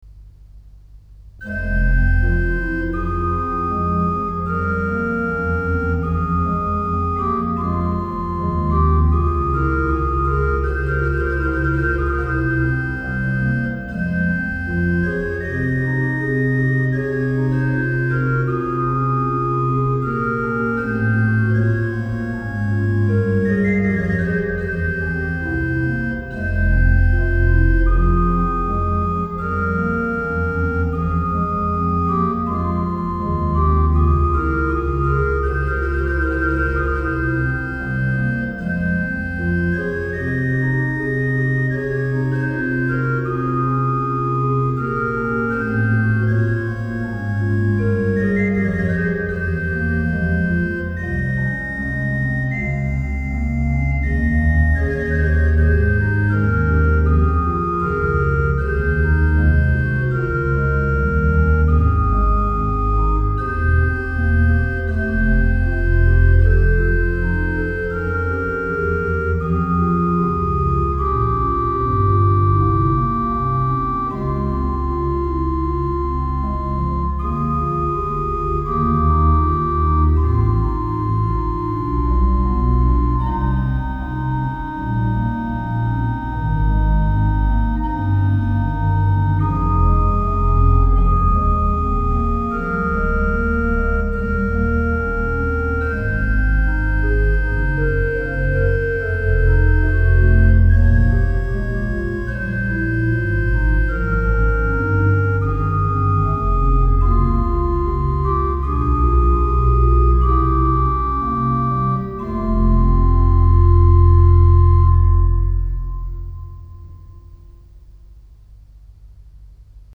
Bach, Johann Sebastian - Orgel Choral - Ich ruf zu dir, Herr Jesu Christ (открыта)